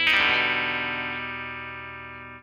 007CHORDS.wav